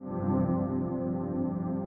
In the case of the Ambient Pads, it is a synth-type pad instrument.
Attack: 100%
Decay: 50%
Sustain: 50%